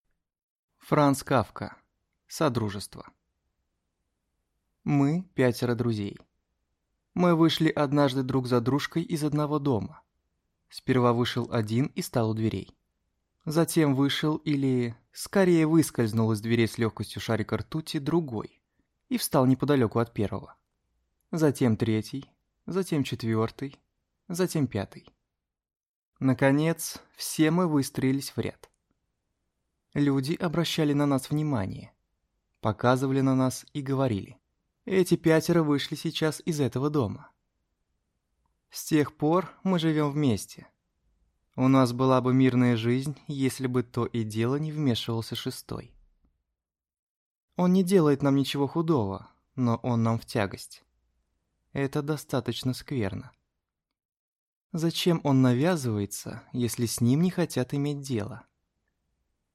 Аудиокнига Содружество | Библиотека аудиокниг